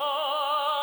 SouthSide Chant (53).wav